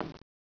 RAILHIT1.WAV